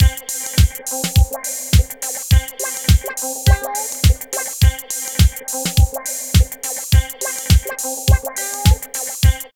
136 LOOP  -L.wav